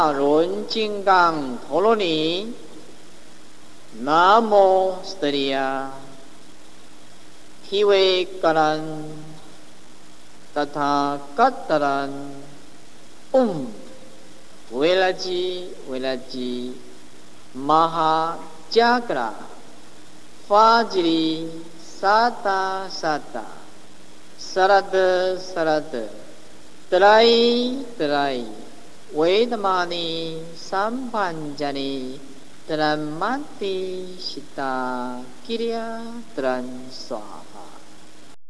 按照此录音发音即可，本站不再另外注音了。